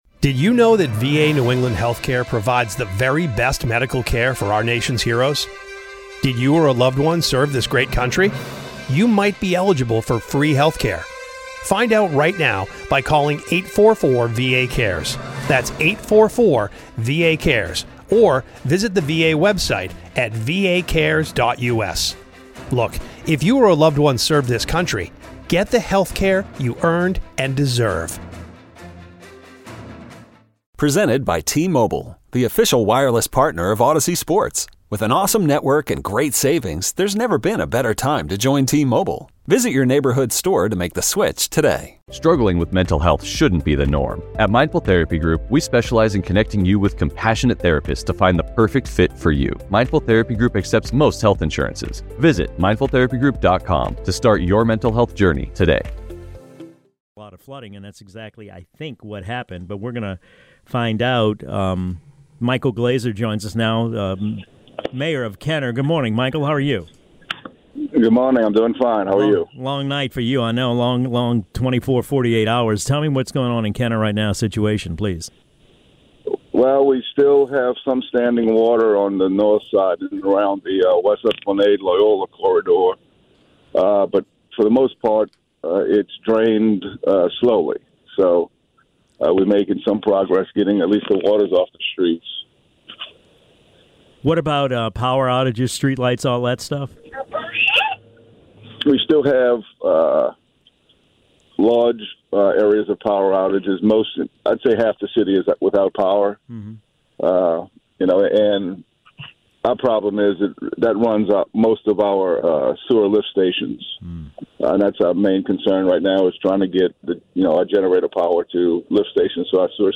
talks with Michael Glaser, the mayor of Kenner